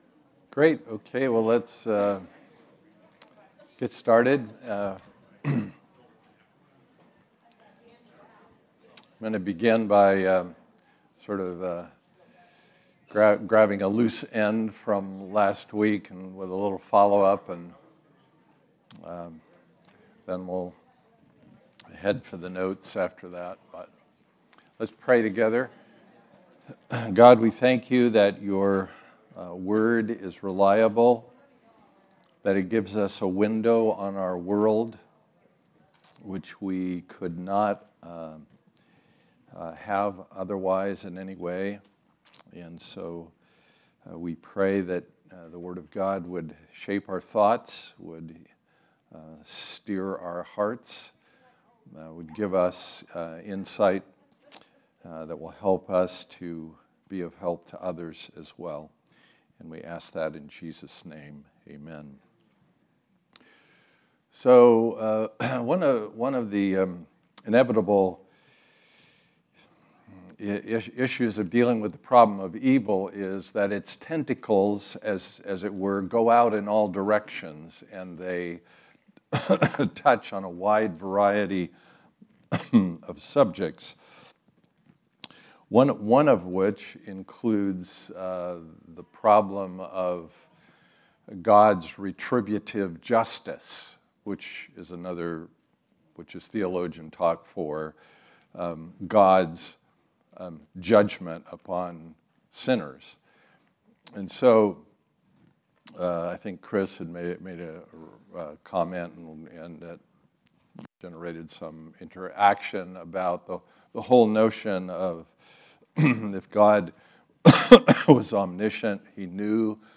All Messages Finding a Good God in a Fractured World: Week 4 September 25, 2022 Series: Why Evil? Type: Sunday School